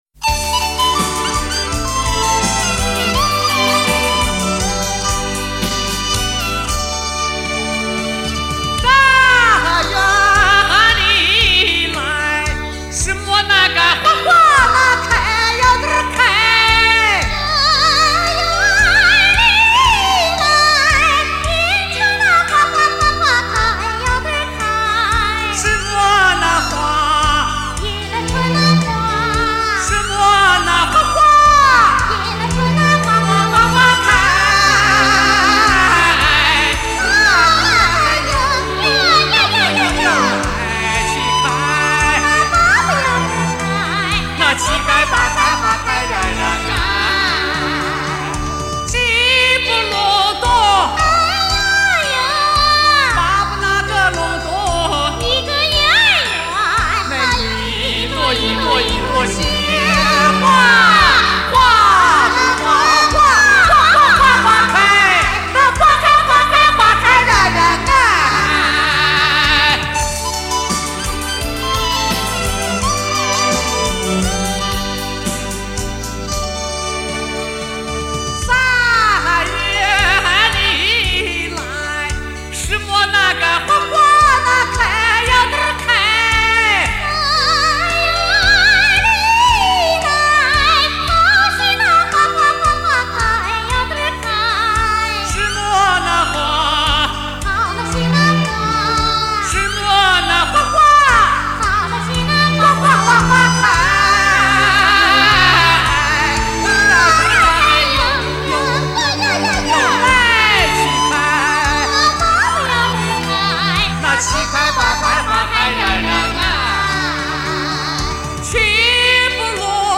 陕北民歌：二人台《五月散花》